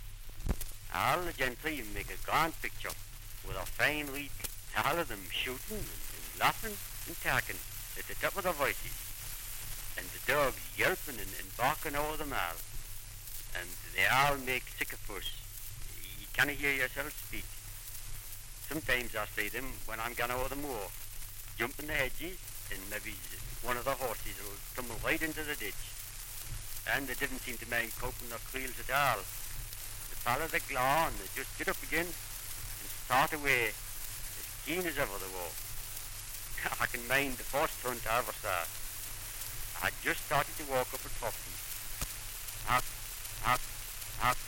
Dialect recording in Rothbury, Northumberland
78 r.p.m., cellulose nitrate on aluminium.